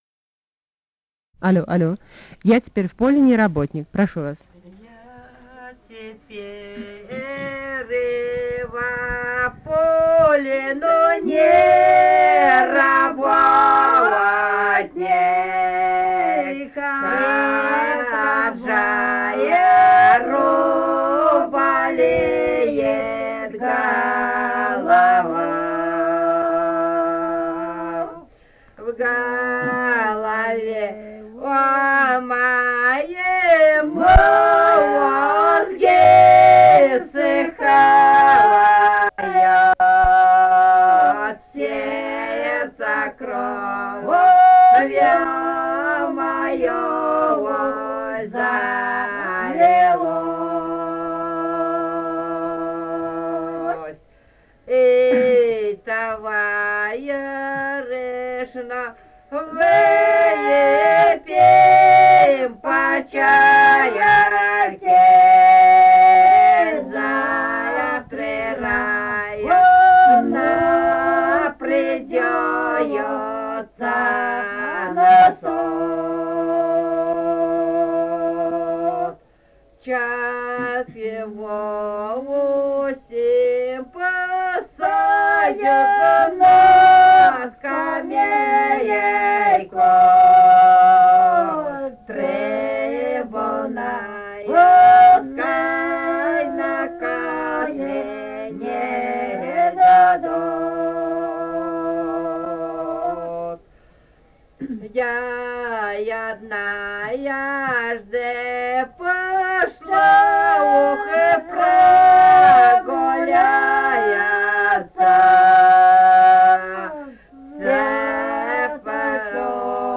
полевые материалы
Бурятия, с. Петропавловка Джидинского района, 1966 г. И0903-07